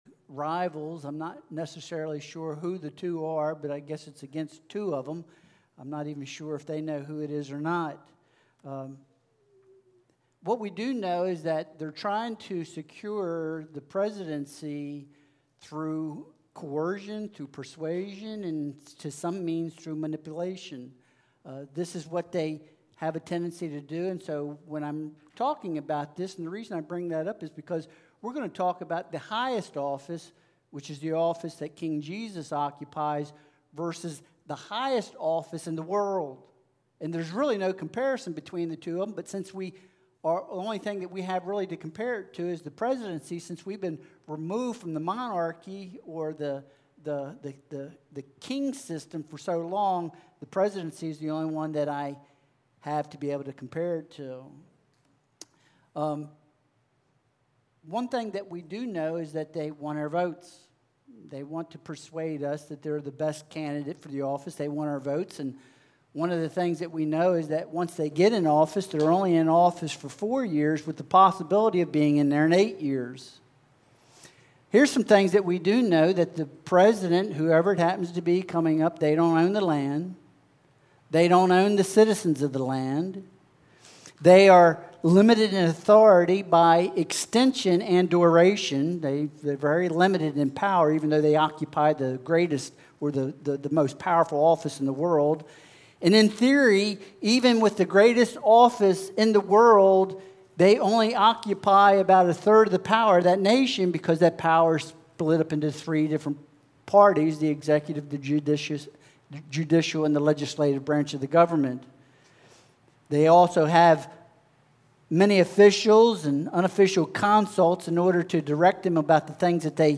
Revelation 19.1-16 Service Type: Sunday Worship Service Download Files Bulletin « Joy Amidst Pain Rehoboam